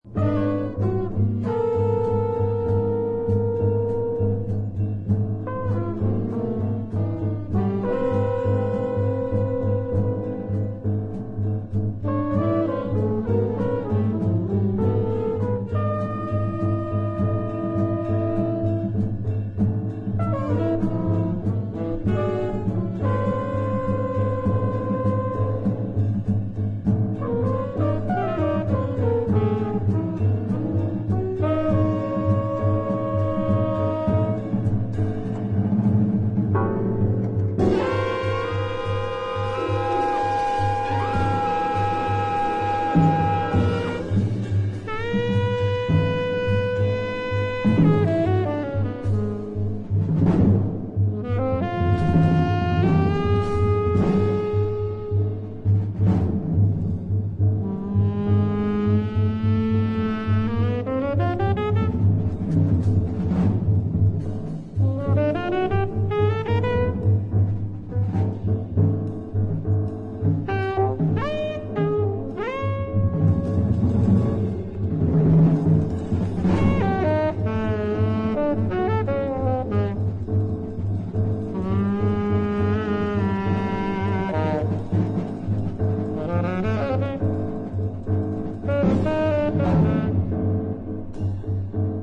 trumpet
trombone
alto saxophone
piano
bass
drums
Jazz